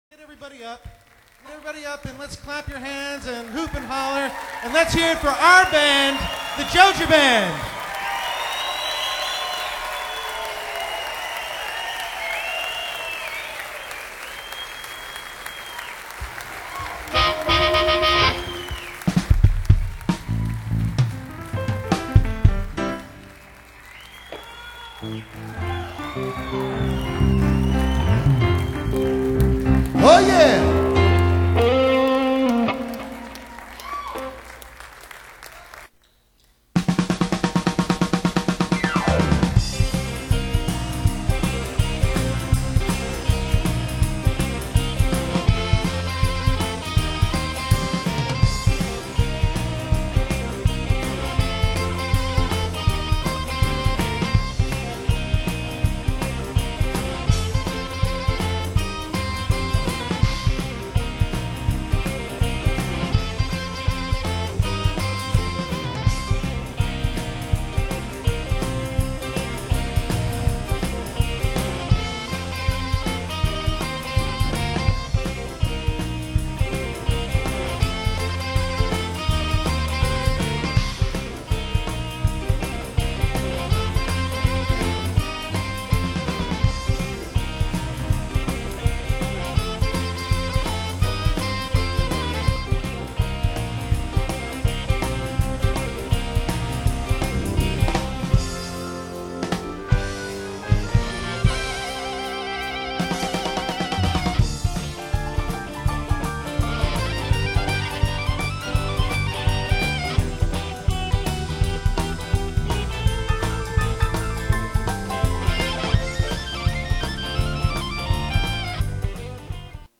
Reunion Sound Clips